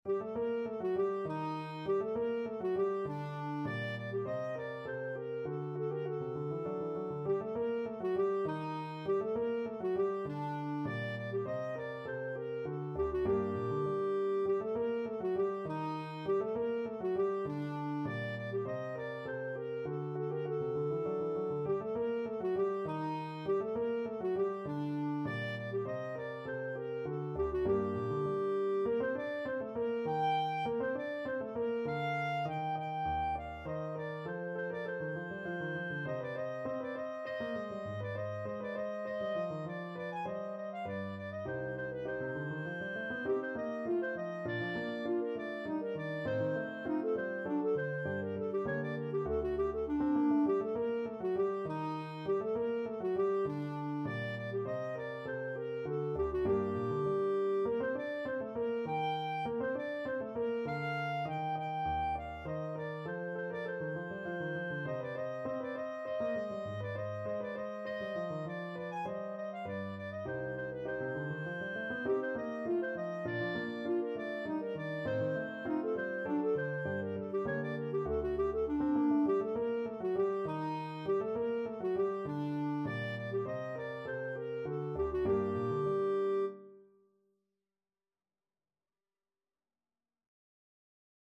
Allegretto = 100
Clarinet  (View more Intermediate Clarinet Music)
Classical (View more Classical Clarinet Music)